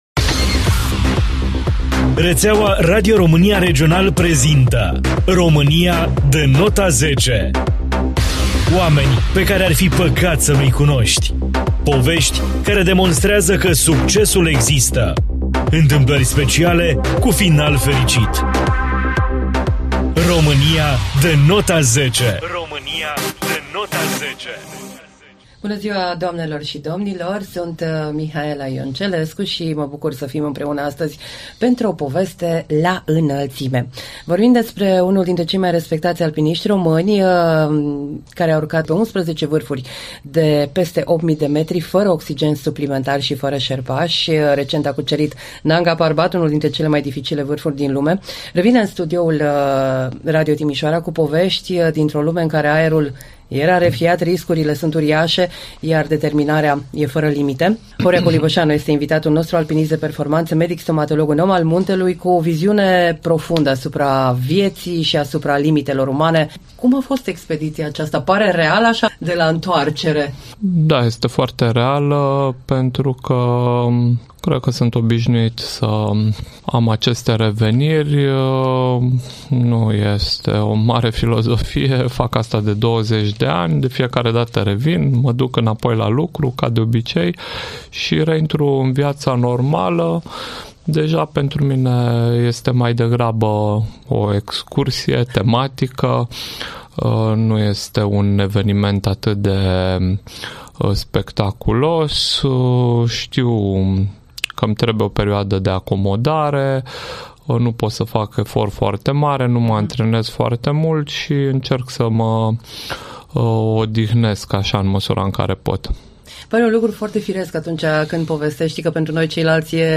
Horia Colibasanu – la microfonul Radio Timișoara